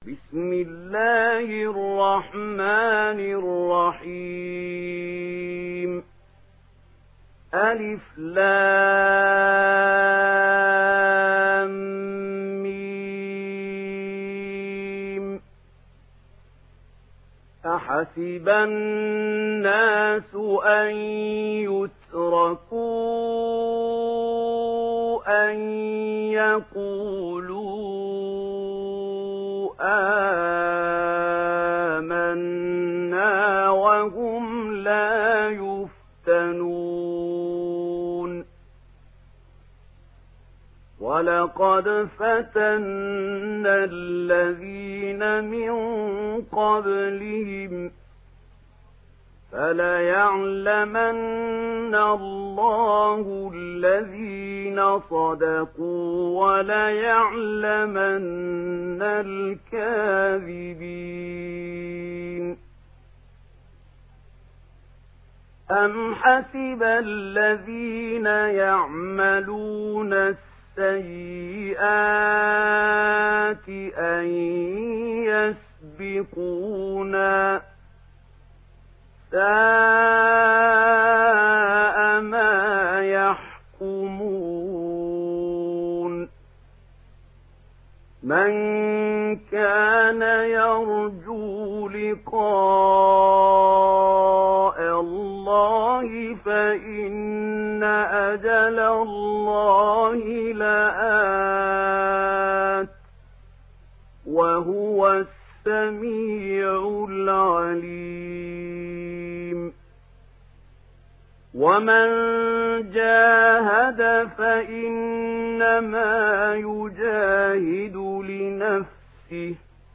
تحميل سورة العنكبوت mp3 بصوت محمود خليل الحصري برواية ورش عن نافع, تحميل استماع القرآن الكريم على الجوال mp3 كاملا بروابط مباشرة وسريعة